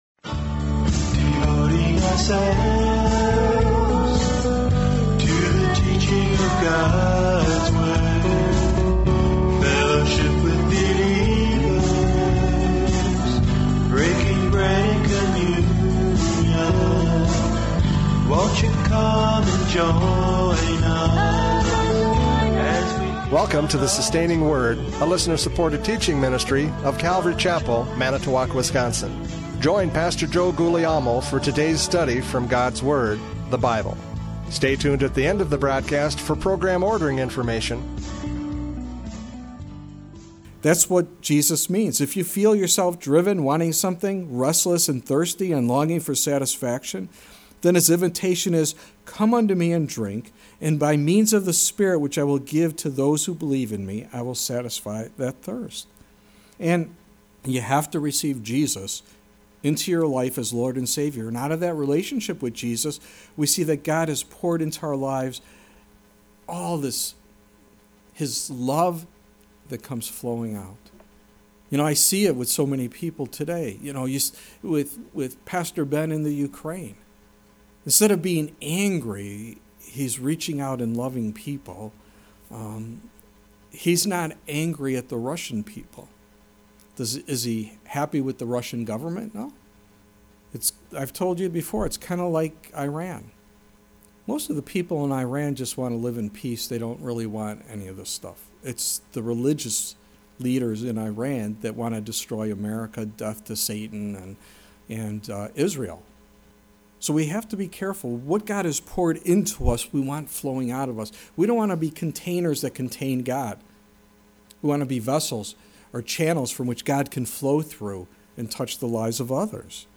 John 7:37-52 Service Type: Radio Programs « John 7:37-52 Truth Divides!